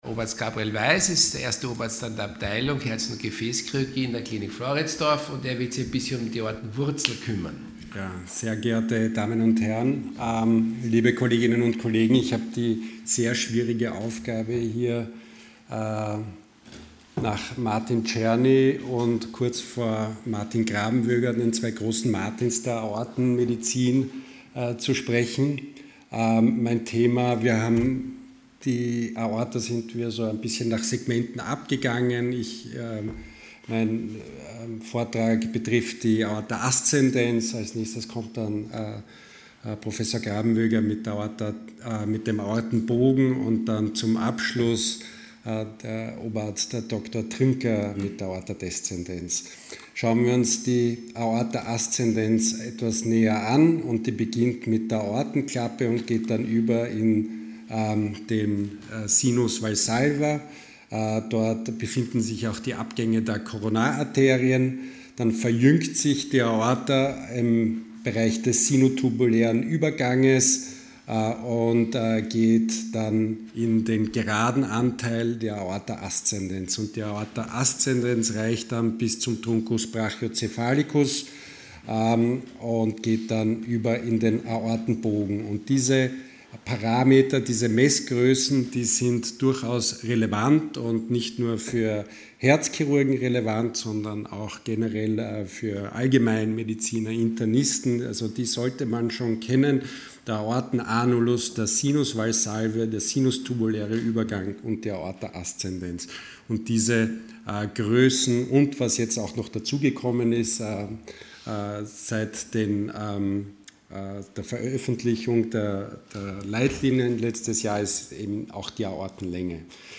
Hybridveranstaltung